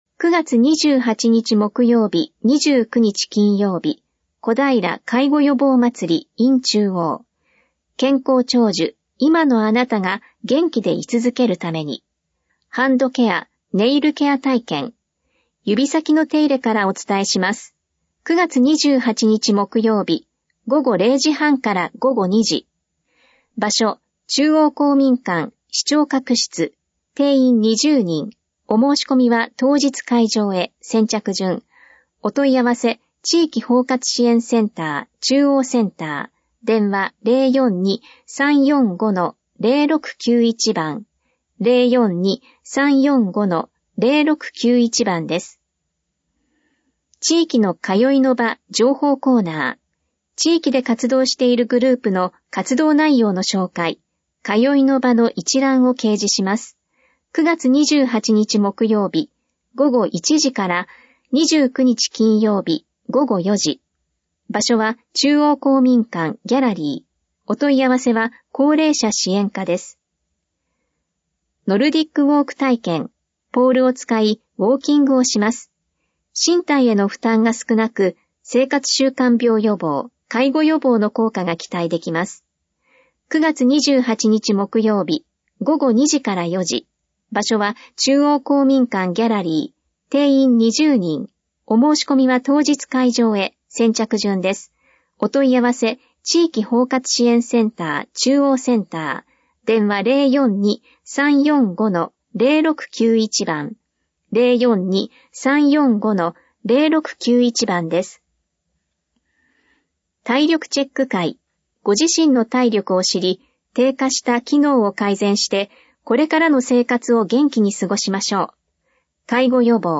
市報こだいら2023年8月20日号音声版